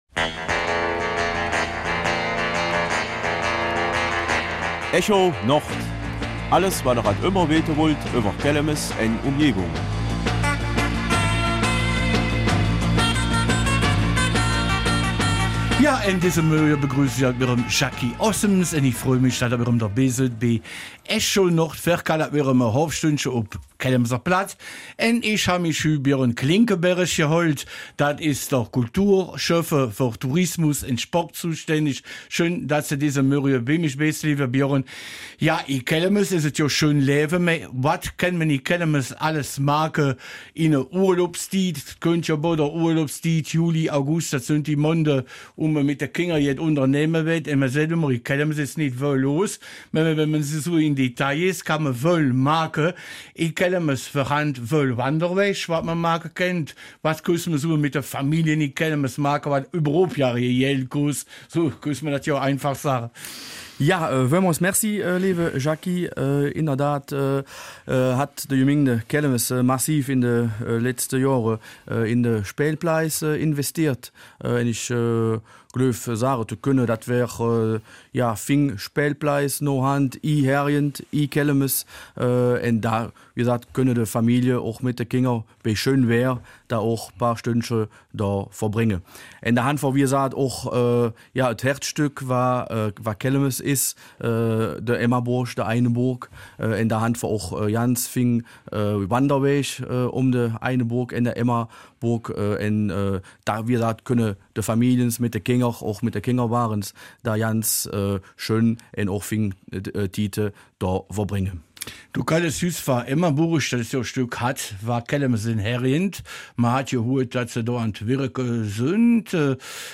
Kelmiser Mundart: Ferienanimation in Kelmis
Zu Gast ist Schöffe Björn Klinkenberg zum Thema Ferienanimation 2025 in Kelmis für diejenigen, die nicht in Urlaub fahren, aber doch etwas unternehmen möchten.